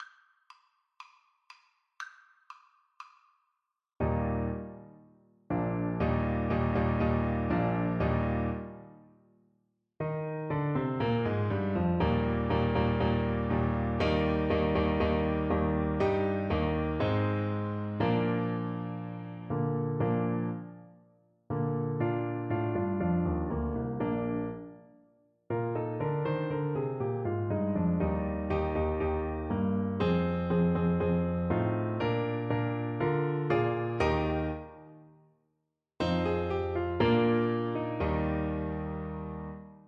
2/2 (View more 2/2 Music)
Brightly = c. 60
Classical (View more Classical Clarinet Music)